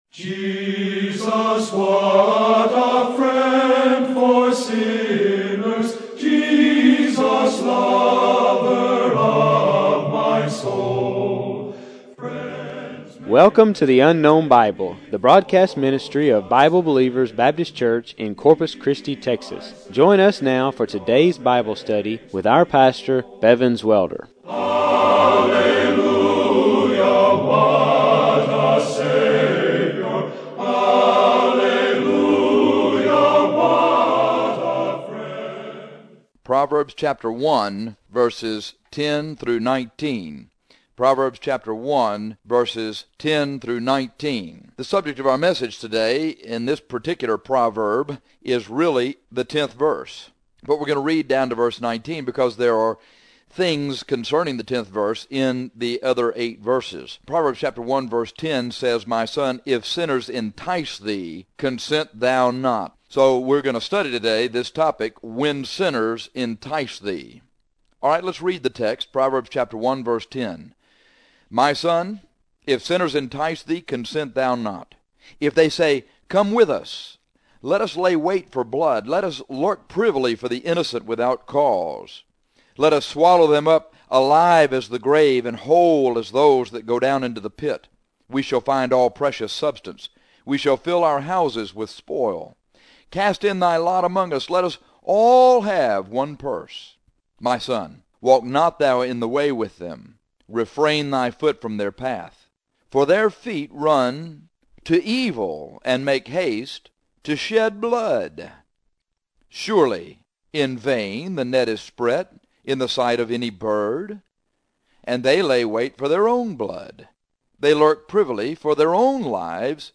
When sinners entice thee, the Bible says Consent Thou Not! This radio broadcast from Proverbs shows you what to do to help you consent not!